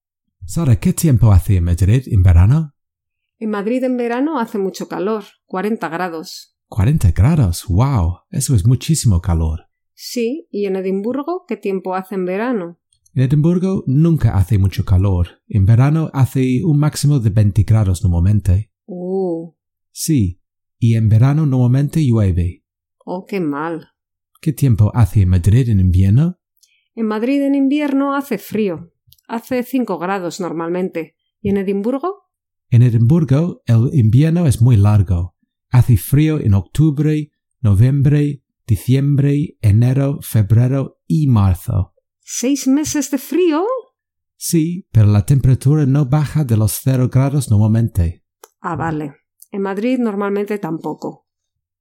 4.-Listening-Practice-The-Weather-Part-2.mp3